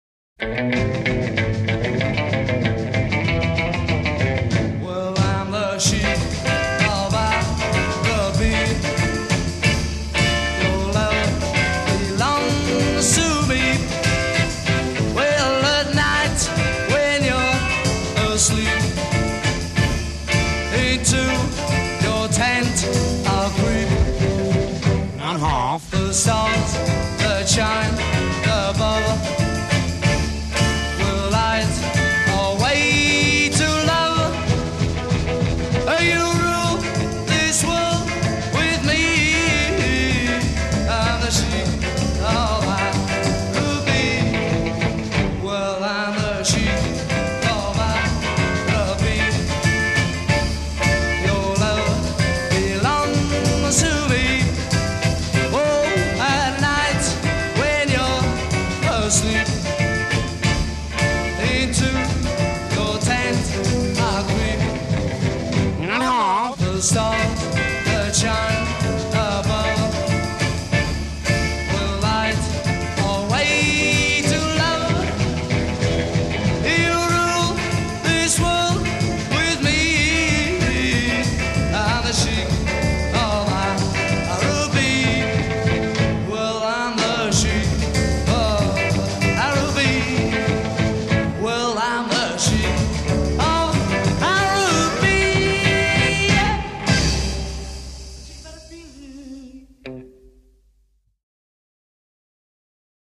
bass
drums
x Intro 0:00 4 Guitar solo with cliché Arab theme.
A1 Verse 1 0:06 16 vocal solo with ensemble a
A' Coda 1:26 8 repetition of the hook